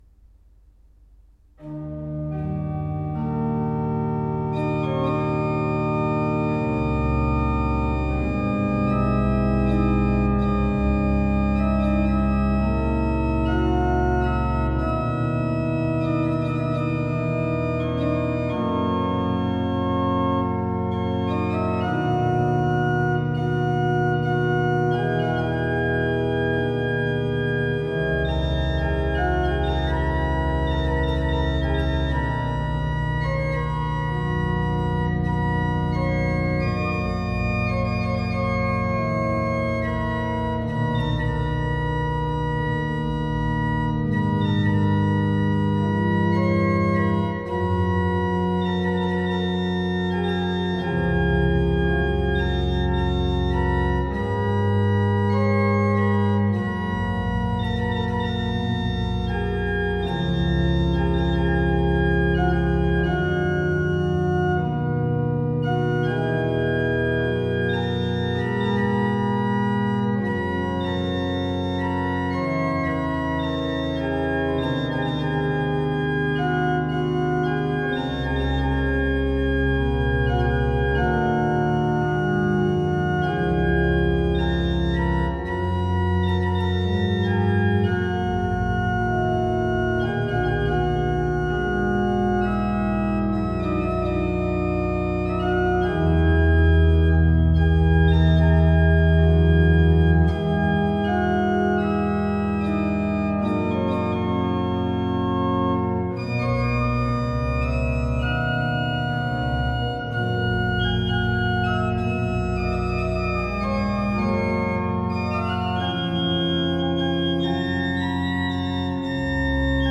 Banque de son de l'orgue Isnard de la basilique de Saint-Maximin-la-Sainte-Baume
À l'orgue Hauptwerk Mixtuur-II, Le Vauroux, enregistrement le 27 octobre 2023